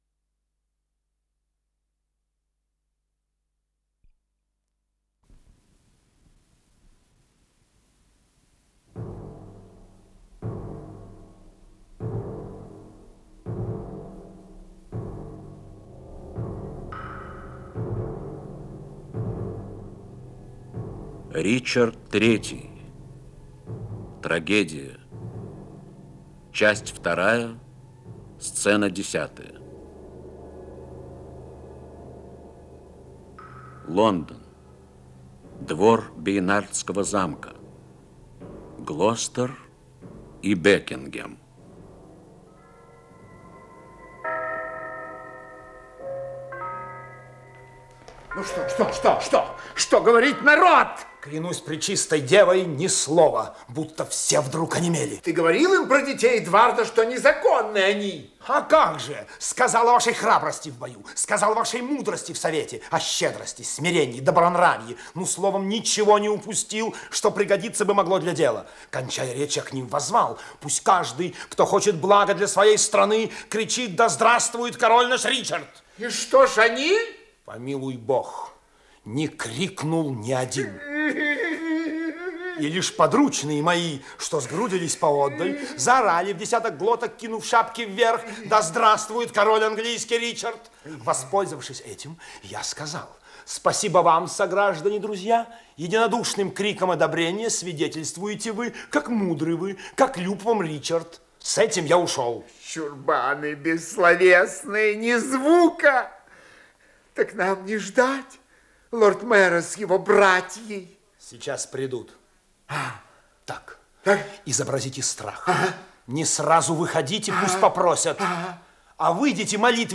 Исполнитель: Артисты Московского театра им. Е. Вахтангова
Радиокомпозиция